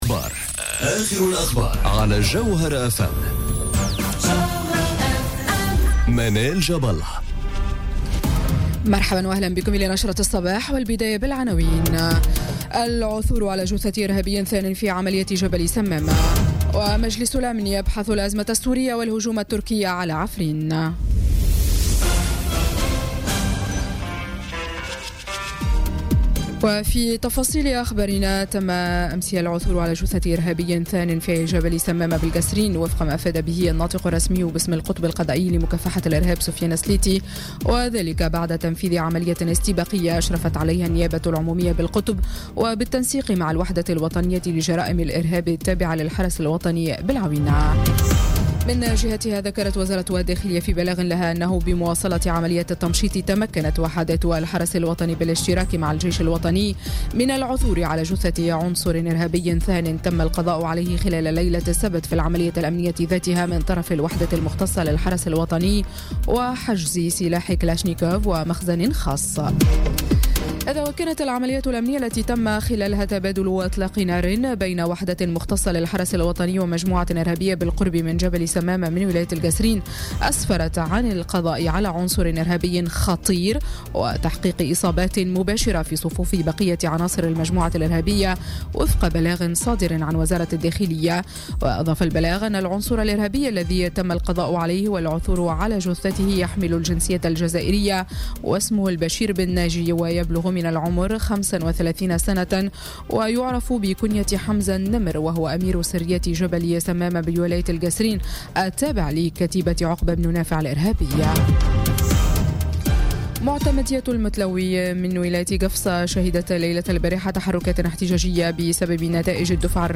نشرة أخبار السابعة صباحاً ليوم الاثنين 22 جانفي 2018